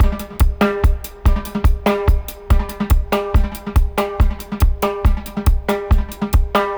142-FX-05.wav